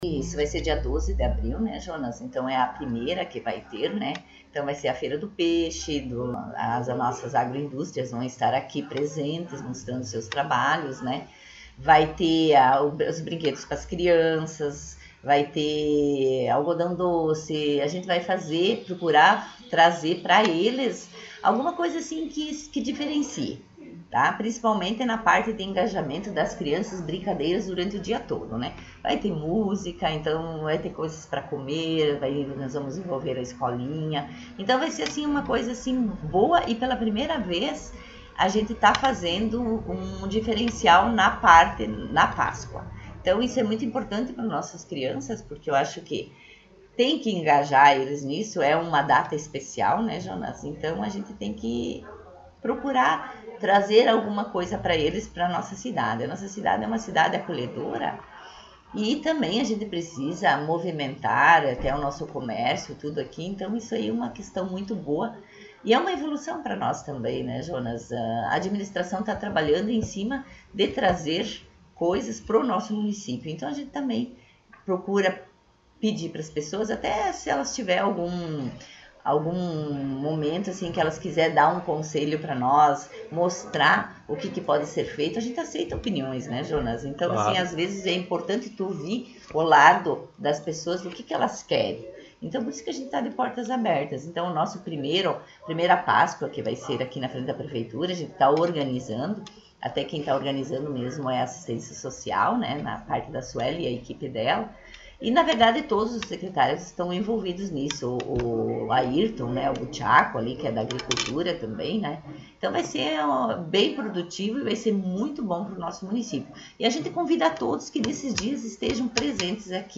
Prefeita em Exercício Marta Mino concedeu entrevista